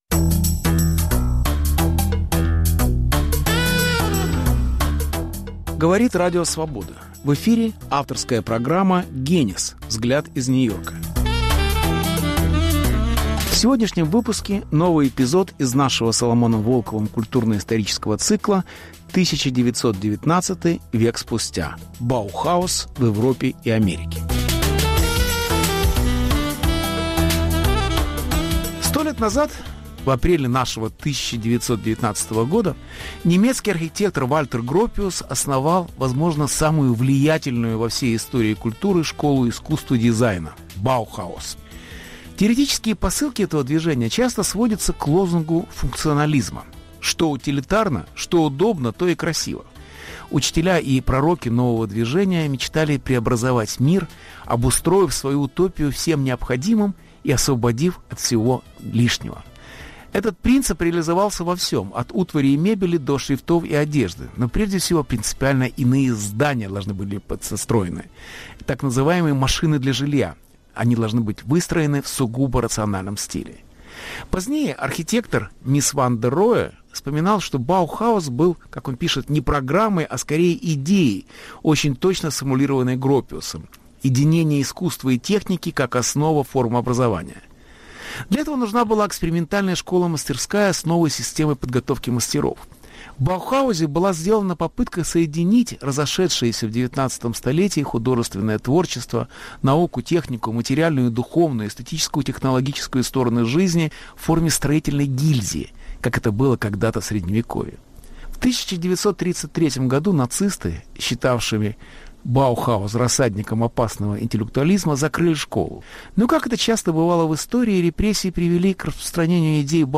Баухаус: его друзья и враги 1919 - век спустя. Беседа с Соломоном Волковым.